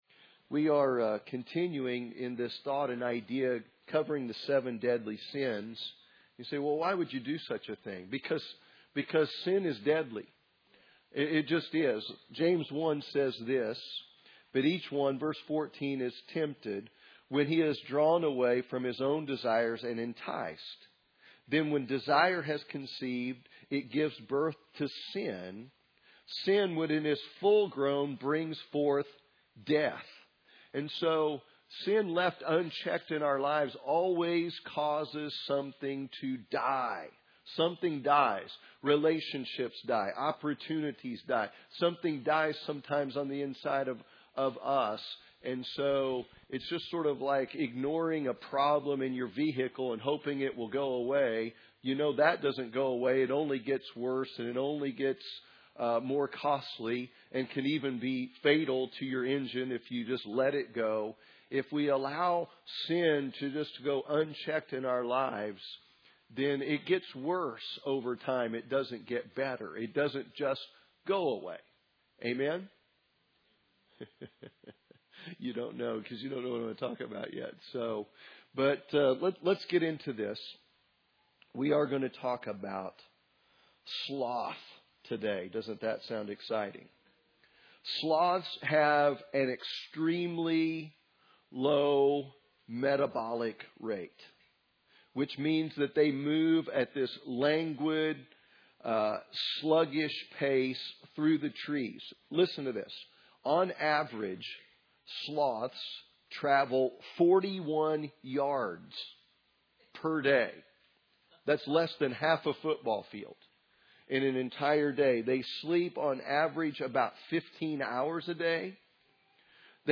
Sunday Morning Service The Seven Deadly Sins